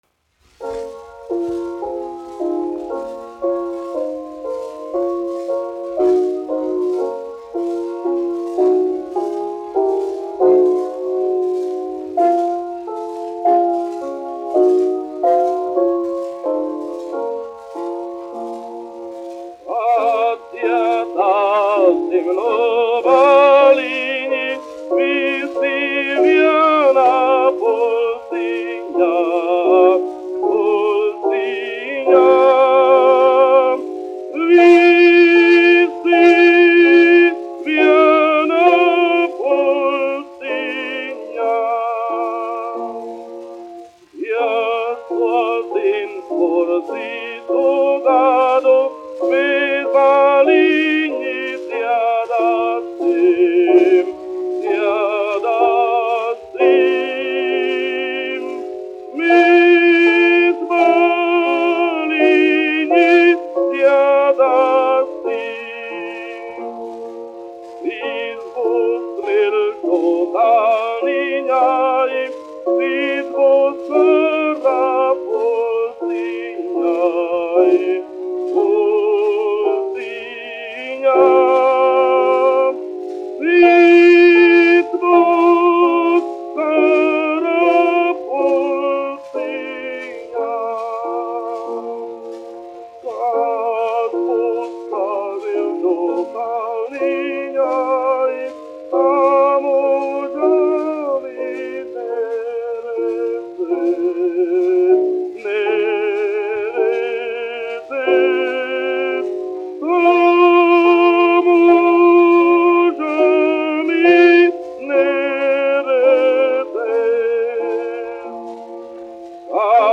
Jāzeps Vītols, 1863-1948, aranžētājs
1 skpl. : analogs, 78 apgr/min, mono ; 25 cm
Latviešu tautasdziesmas
Skaņuplate
Latvijas vēsturiskie šellaka skaņuplašu ieraksti (Kolekcija)